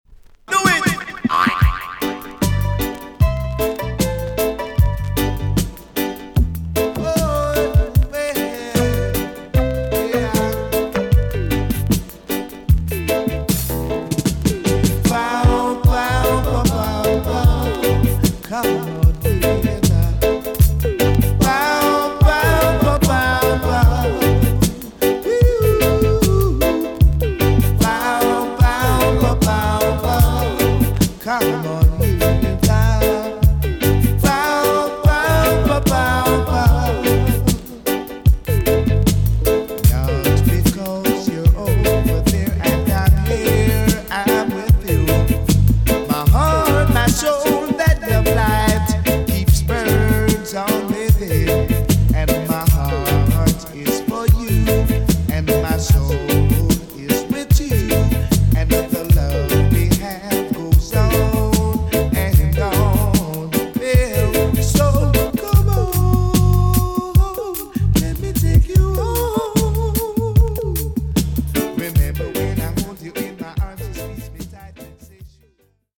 TOP >DISCO45 >VINTAGE , OLDIES , REGGAE
EX- 音はキレイです。
NICE LOVERS ROCK TUNE!!